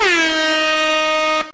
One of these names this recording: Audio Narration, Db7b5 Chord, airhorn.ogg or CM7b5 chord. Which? airhorn.ogg